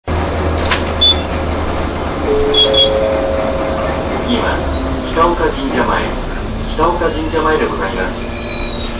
をおッ！！　車内放送チャイムが神奈中のとソックリだッ！（笑）
車内放送「
フルメンズボイスな放送はここが初めてだよ旦那ッ！！　スゲーッ！！